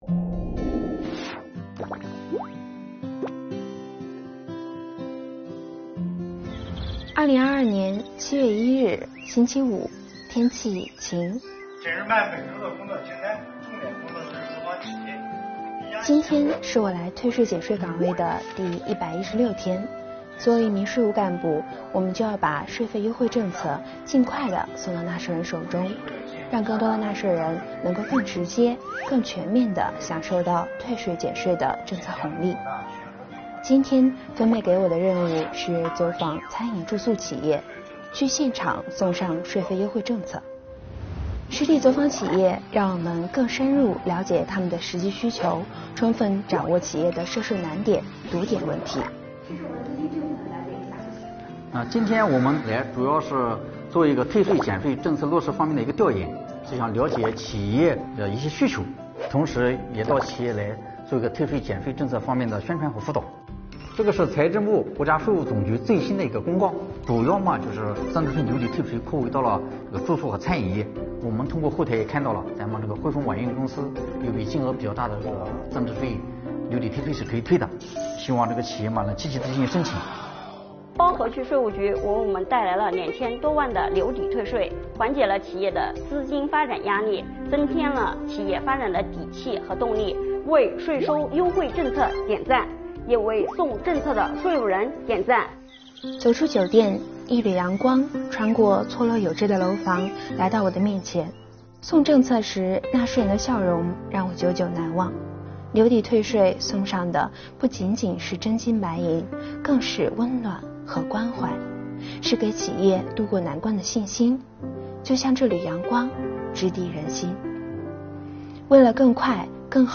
作品通过一线税务干部自述的方式，讲述税务部门落实留抵退税的具体举措和贴心服务，展现留抵退税帮助餐饮住宿业纾困解难的成效。
作品以缓慢而清晰、温柔而有力的方式，展现税务干部如何将税费优惠政策送到纳税人的手中。